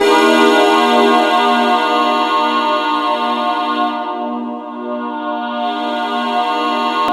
Index of /90_sSampleCDs/Best Service ProSamples vol.10 - House [AKAI] 1CD/Partition C/PADS